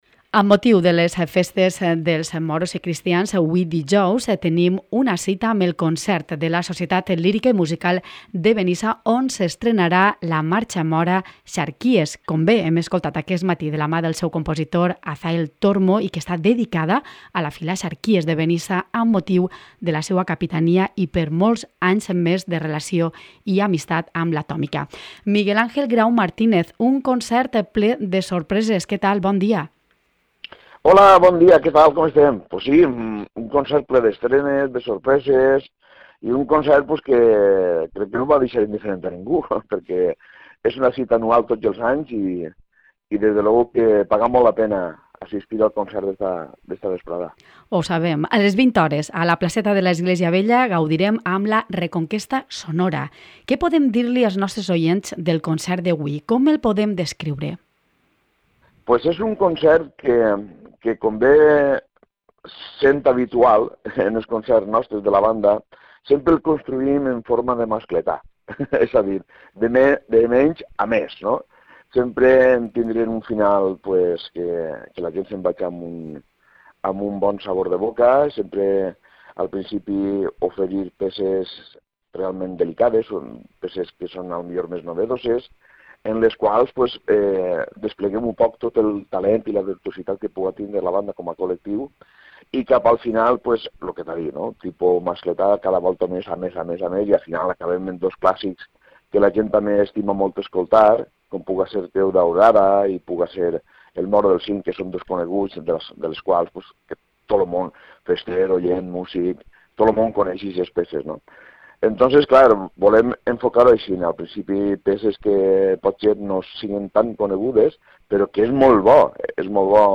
Podcast Entrevistas